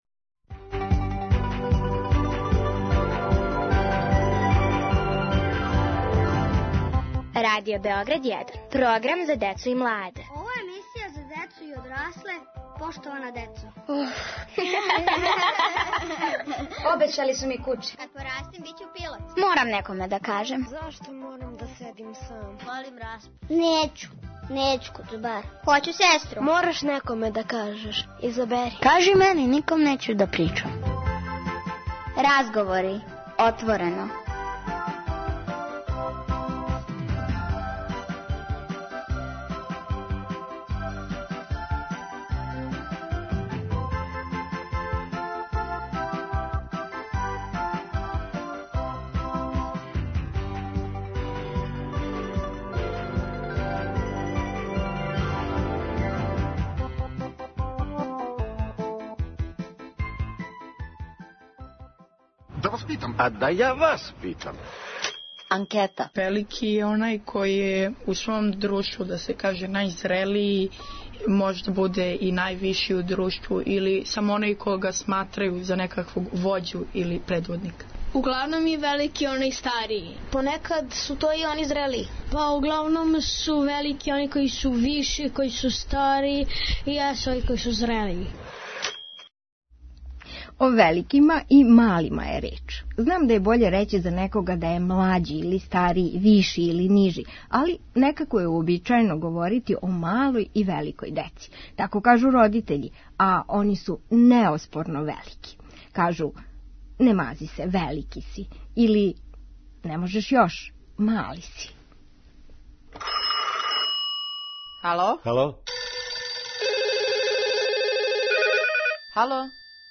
Ове недеље отворено разговарају велики и мали о великима и малима.